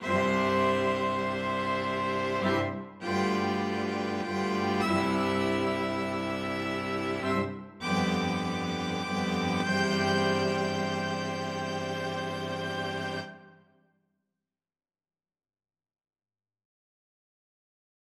여기서 제2전위와 제3전위는 "장엄하고 풍부한 화성"에 기여한다.[6]
베토벤 현악 사중주 Op. 127 1악장, 135–139마디 (오디오)